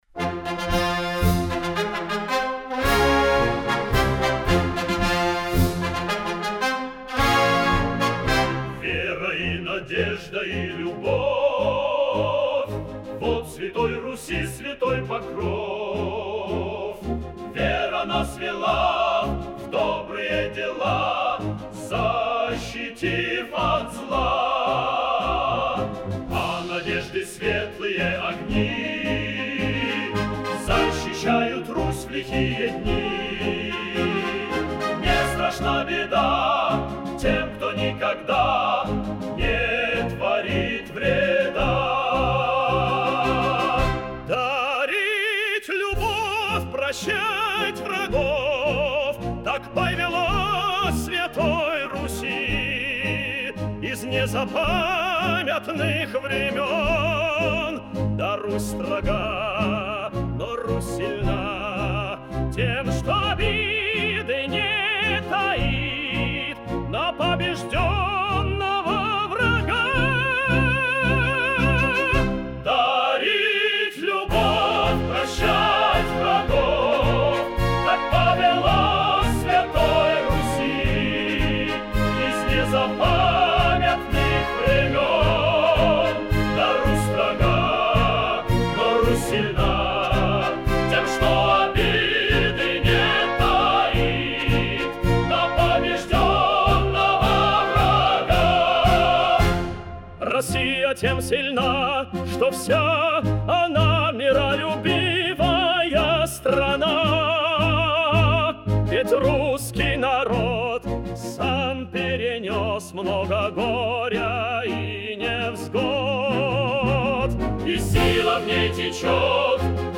на мотив старинного русского марша